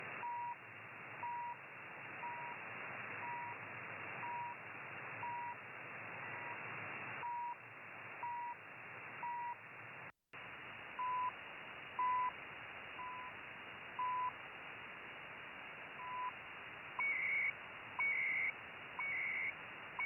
Reception on shortwave
Mode: USB
BW: 2.8KHz